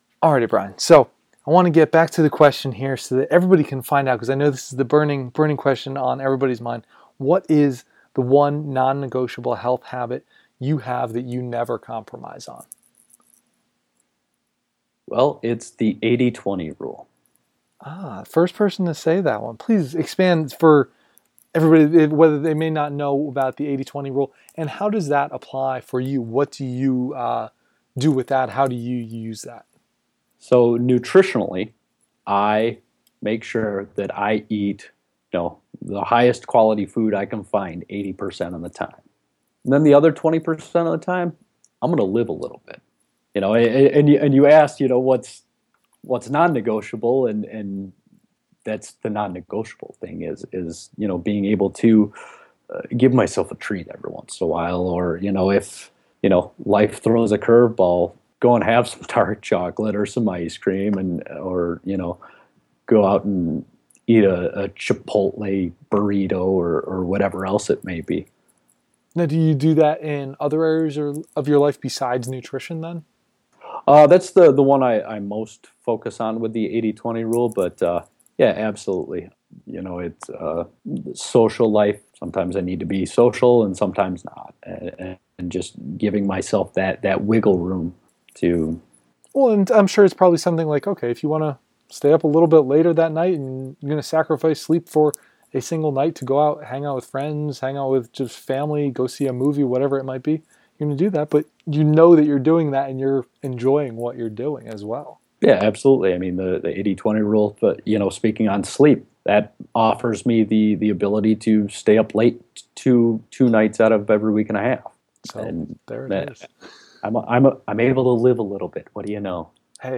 by | posted in: BNHP Interviews, Podcast | 0